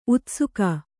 ♪ utsuka